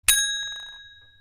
CallBell.mp3